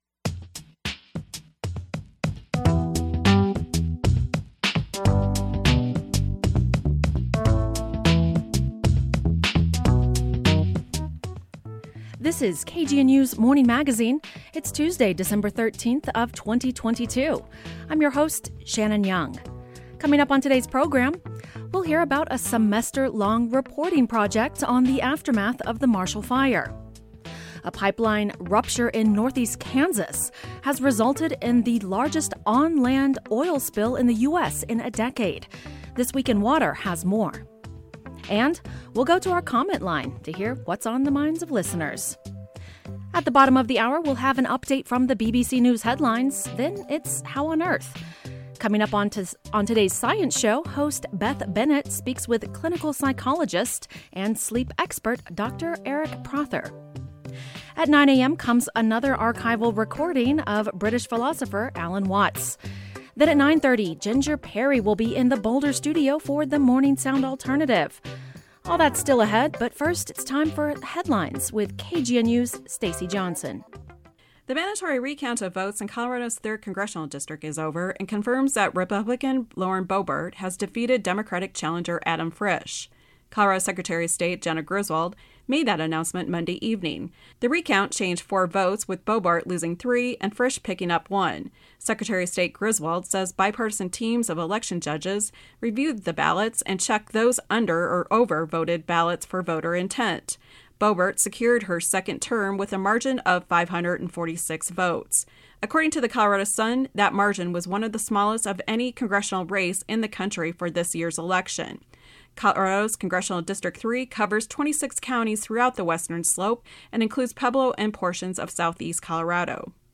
On today’s Morning Magazine, we’ll hear about a semester long reporting project on the aftermath of the Marshall Fire and This Week in Water has more on a pipeline rupture in northeast Kansas that has resulted in the largest inland oil spill in the U.S. in a decade. We’ll also go to our comment line to hear what’s on the minds of listeners.